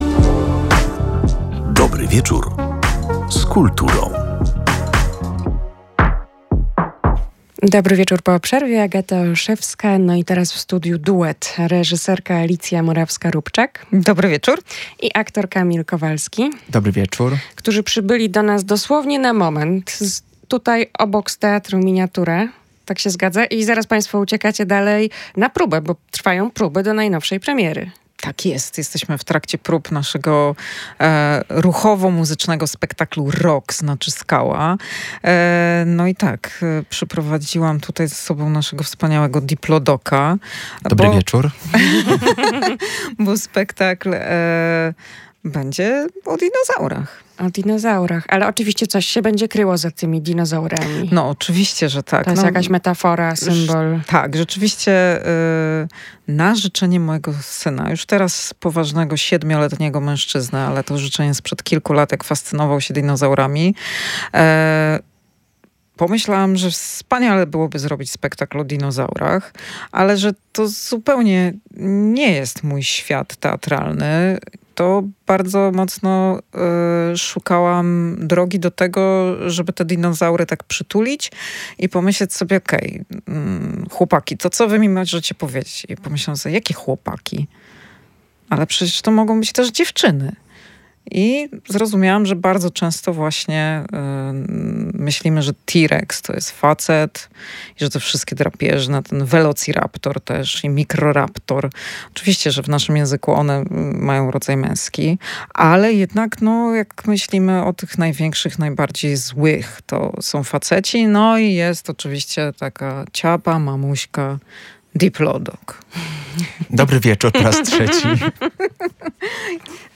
O tym, jak się tworzy przedstawienie wciągające kilka pokoleń, oraz o tym, skąd pomysł na dinozaury w teatrze, w rozmowie